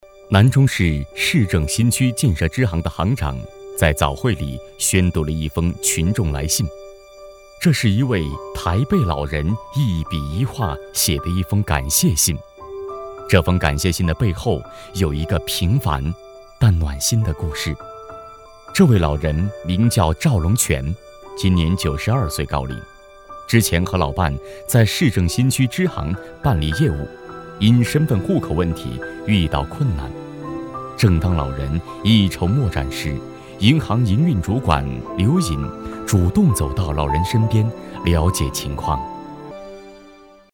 稳重磁性 人物专题
浑厚稳重男中音，大气激情，磁性。